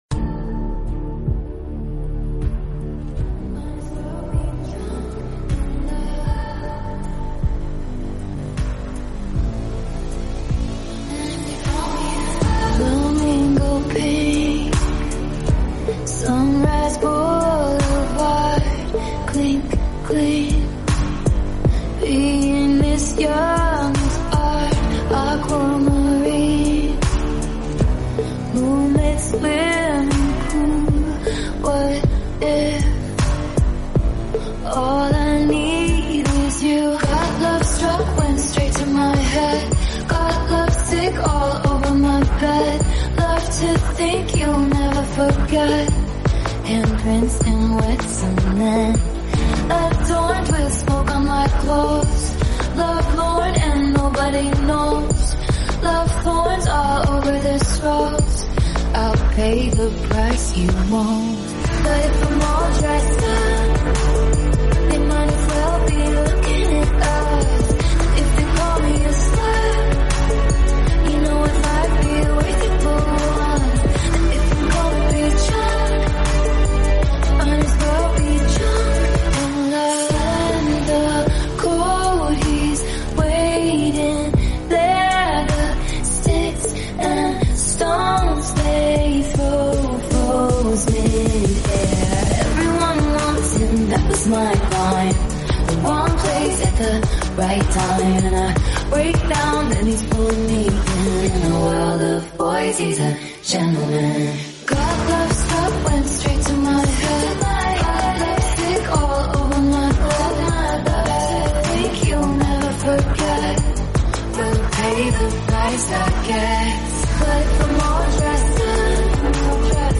Synth Pop Version
remix